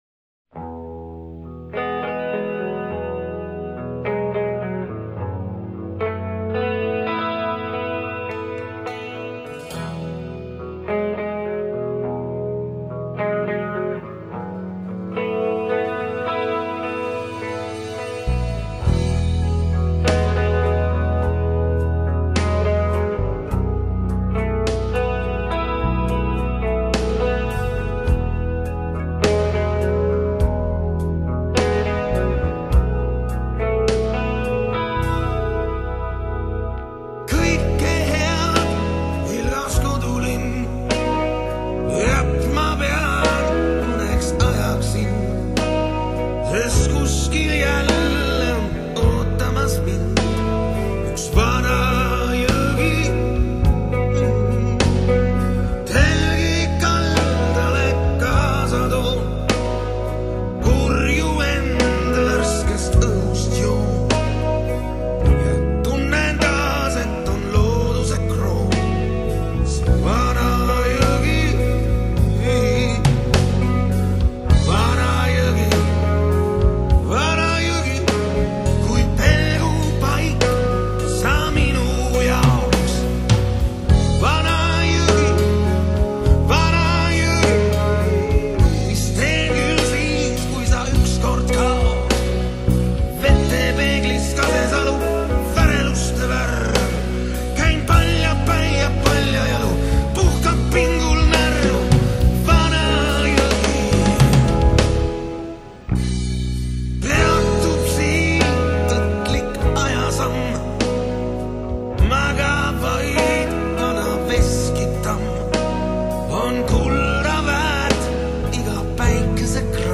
А мне показался отличный блюзок.